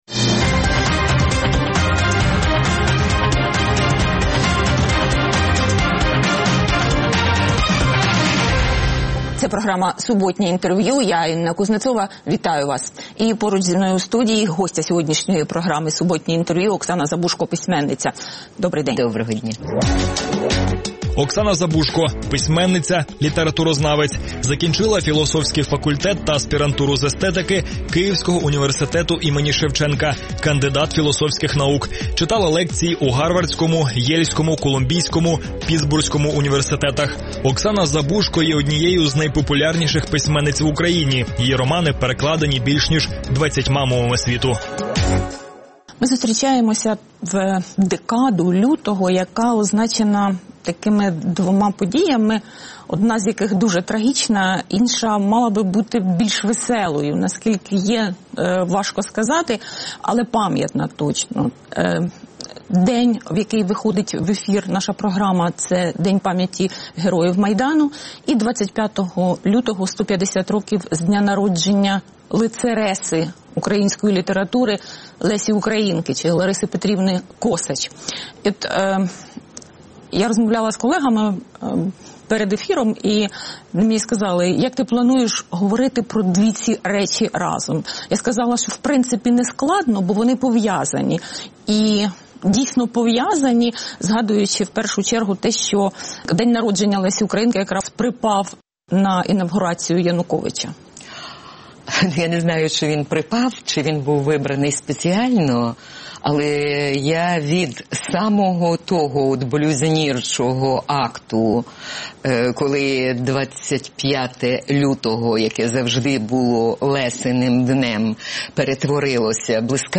Суботнє інтерв’ю | Оксана Забужко, письменниця